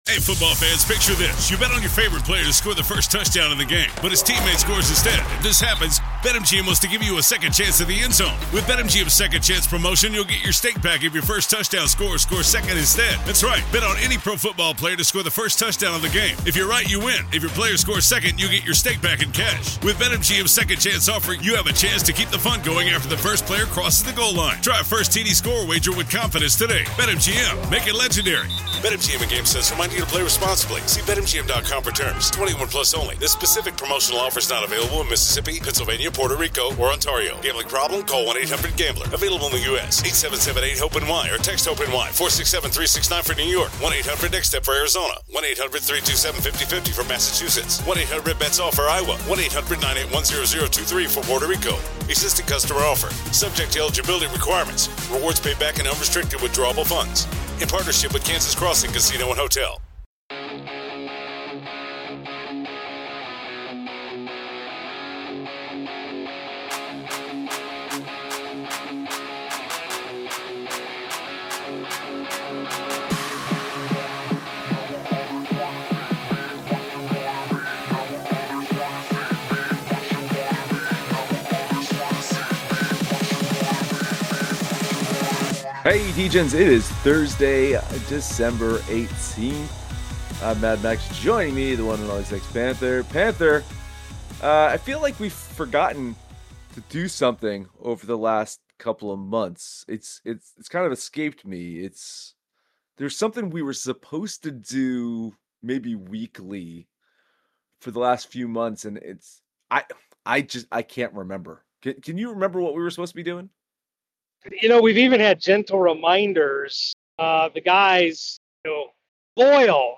Sports betting conversations from gambling degenerates. Every week we talk about our bets in football, basketball, baseball, hockey and other things.